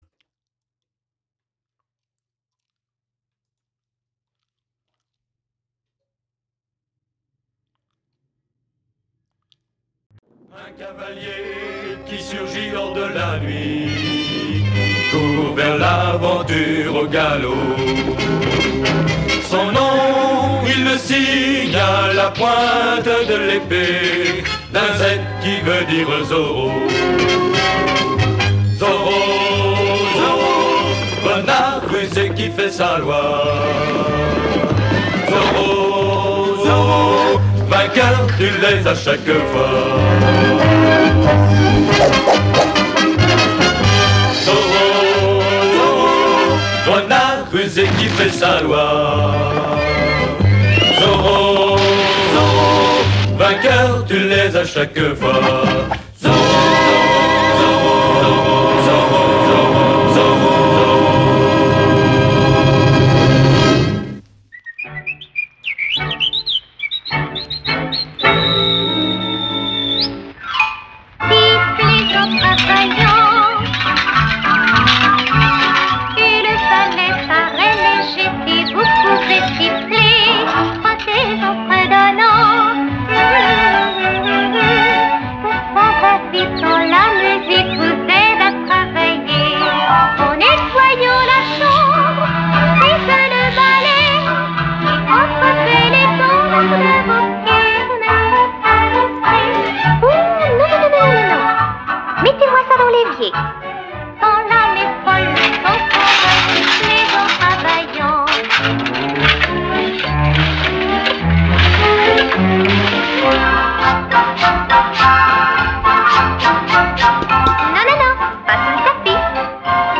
classé dans : musique